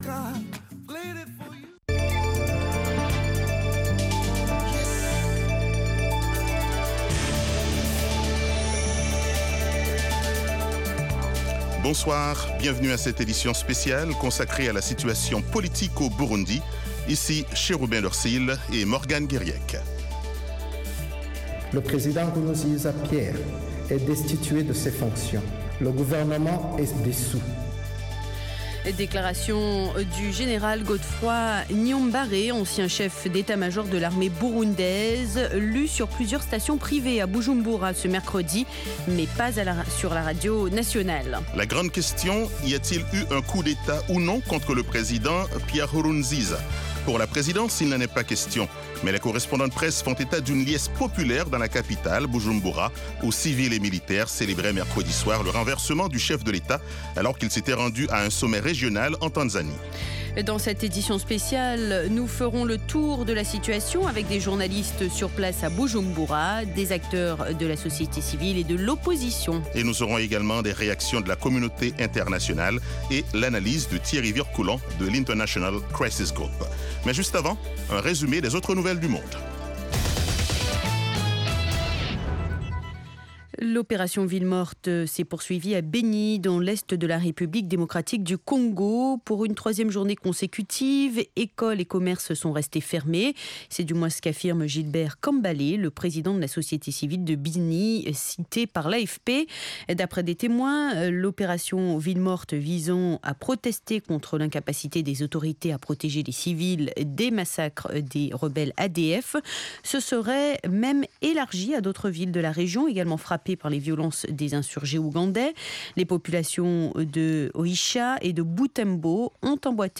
Le Monde Aujourd'hui Interviews, reportages de nos envoyés spéciaux et de nos correspondants, dossiers, débats avec les principaux acteurs de la vie politique et de la société civile. Aujourd'hui l'Afrique Centrale vous offre du lundi au vendredi une synthèse des principaux développementsdans la région.